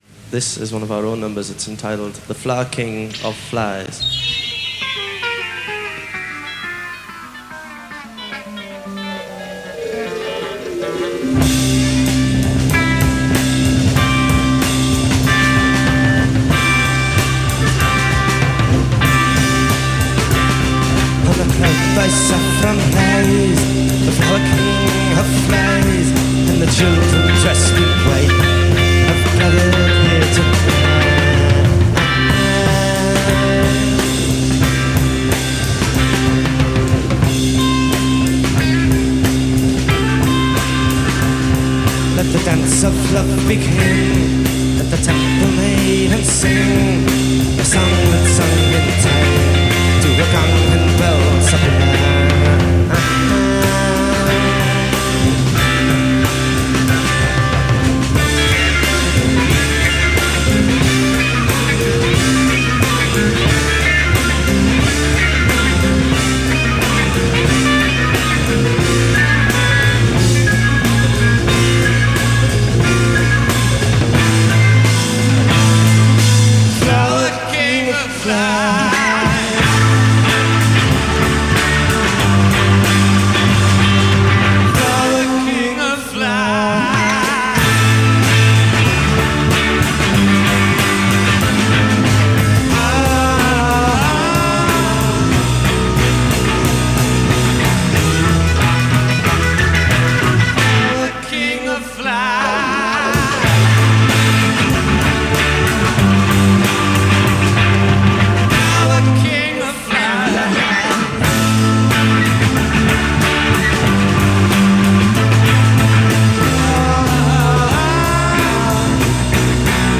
keyboard maestro